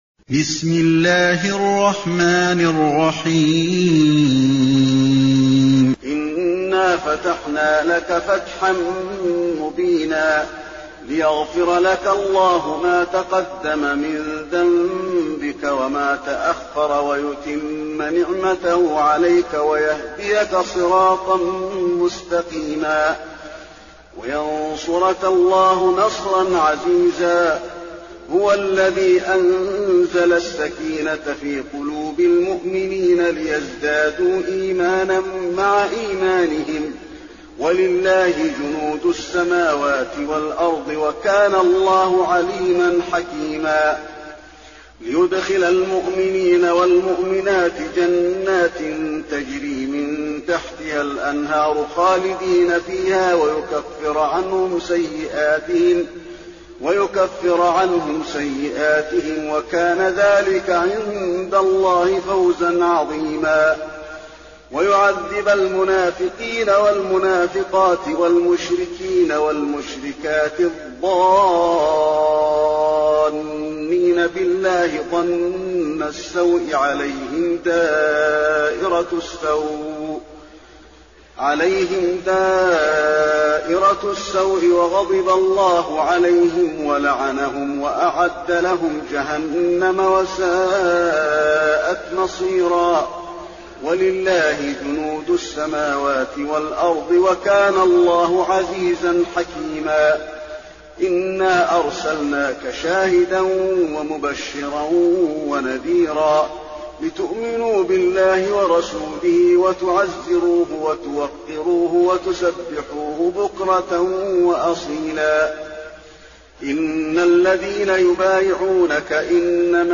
المكان: المسجد النبوي الفتح